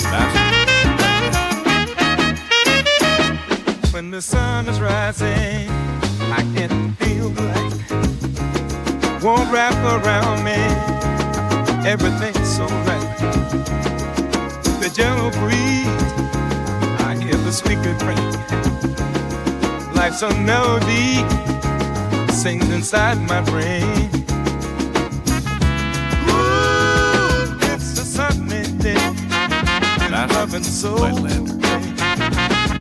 • 開く：高音の抜けとパンチ感のあるモダンな音
• 高：インパクトのある音圧、EDMやヒップホップ向き
今回は、udioで生成した楽曲を「スタイル：開く、音圧：高」の設定でマスタリングしました。
＜マスタリング後の楽曲＞
• 音に立体感と臨場感が加わった
• ボーカルや低音が明瞭になった
• 全体的にクリーンな音質に整った